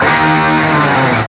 Index of /90_sSampleCDs/Roland L-CDX-01/GTR_GTR FX/GTR_Gtr Hits 1
GTR DIG D0NR.wav